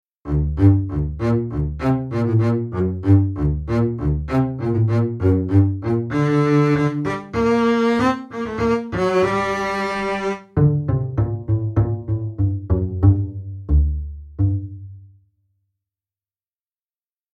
3 种连奏模式 对三种连奏模式
这是演奏者以尖锐的渐强和快速加重音调的弓声结束音符的声音。